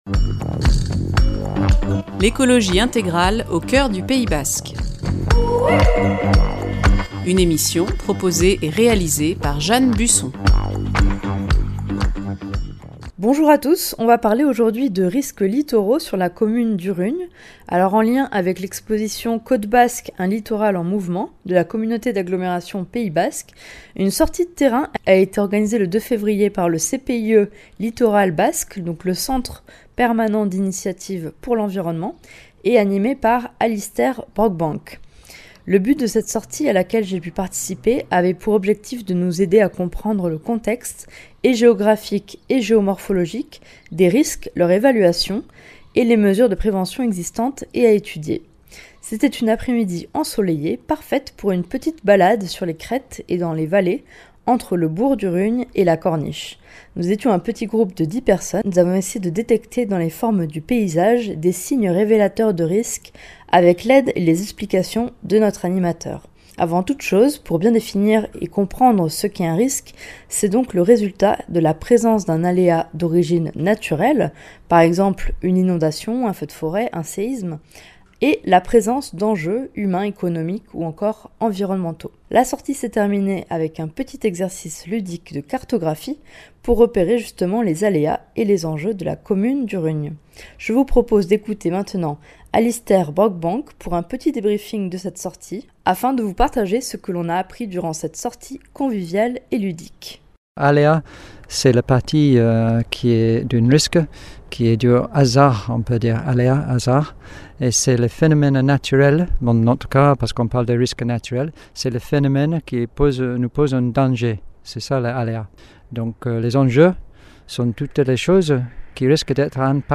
Débrief d’une sortie de terrain sur les risques littoraux sur la commune d’Urrugne associée à l’exposition "Côte basque, un littoral en mouvement" de la Communauté d’Agglomération Pays basque (CAPB).